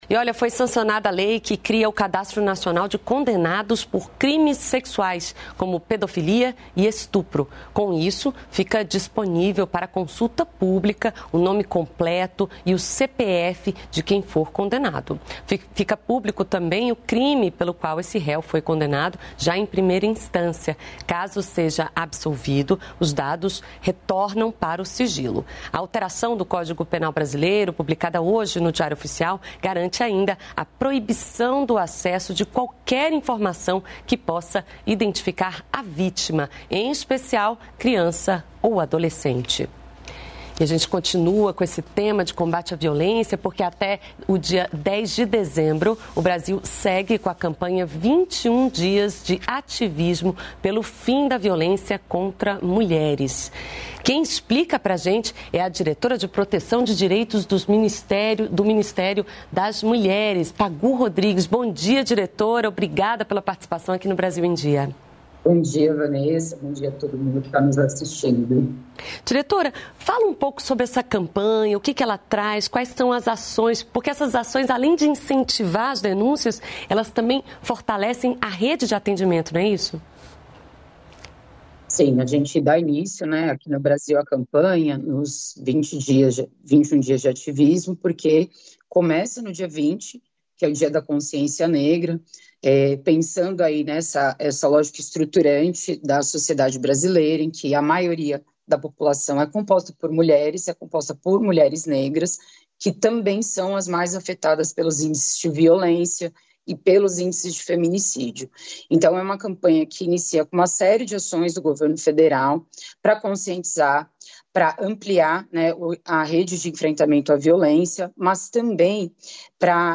Entrevista com Vanderlei Barbosa Santos, Diretor de Benefício e relacionamento cidadão do INSS